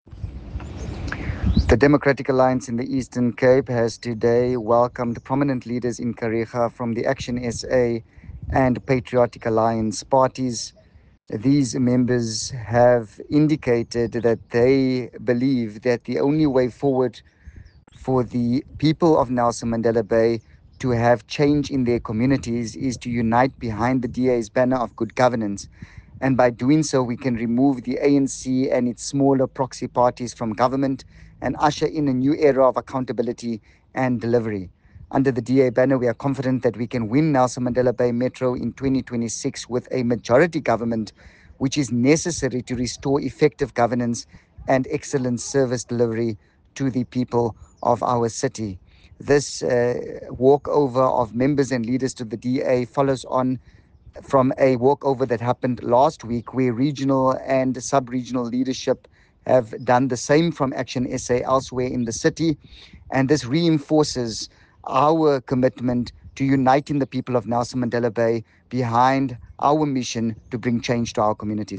soundbite by Yusuf Cassim MPL